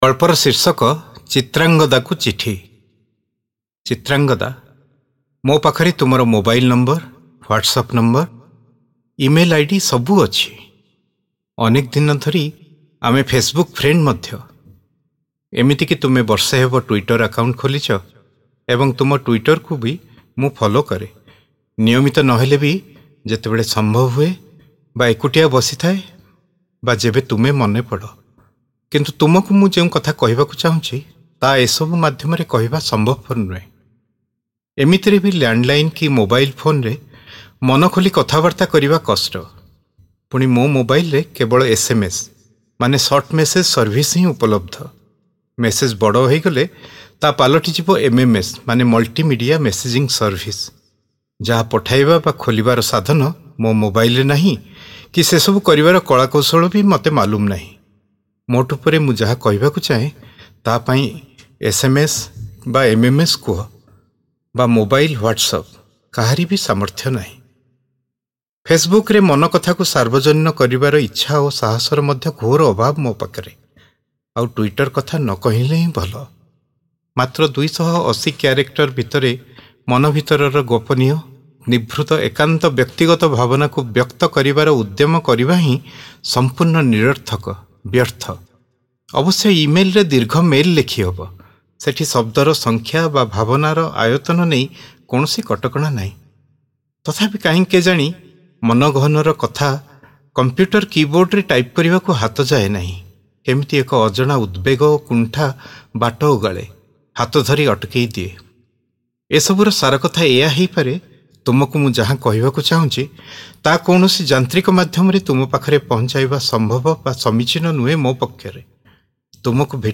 ଶ୍ରାବ୍ୟ ଗଳ୍ପ : ଚିତ୍ରାଙ୍ଗଦାଙ୍କୁ ଚିଠି